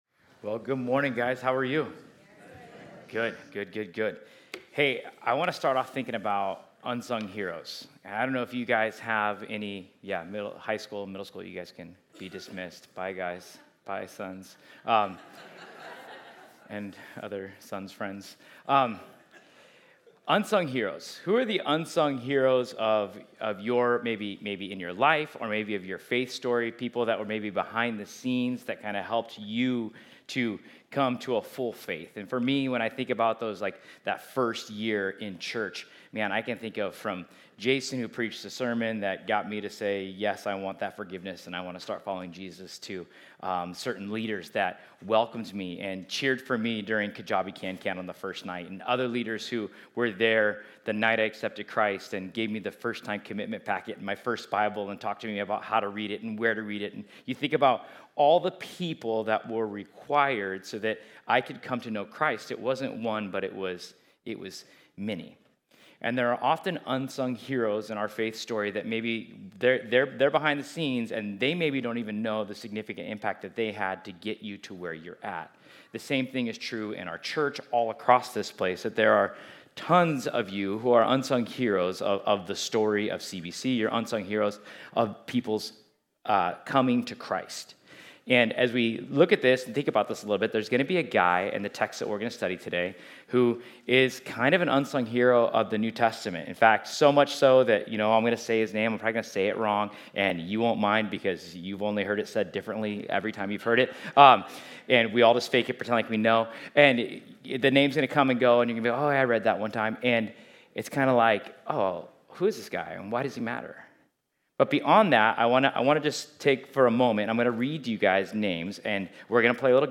Ephesians 6:10-18 Service Type: Sunday We’ve reached Paul’s closing words to the Ephesians.